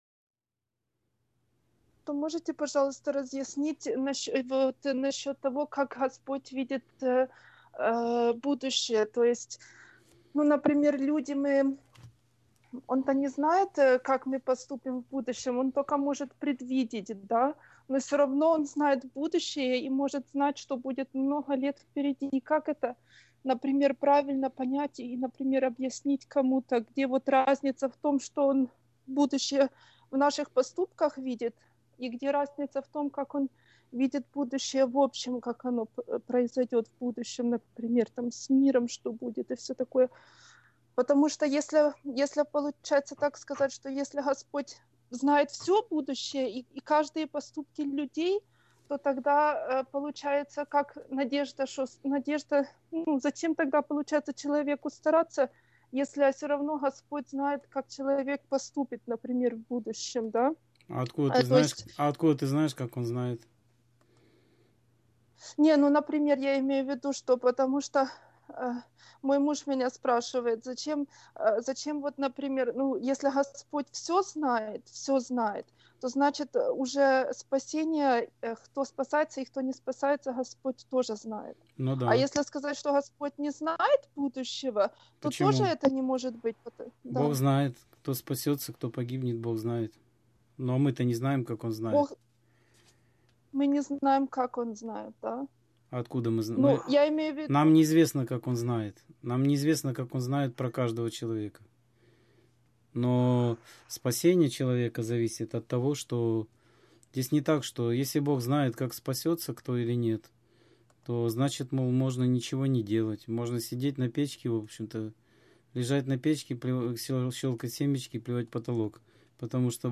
Скайп-беседа 3.09.2016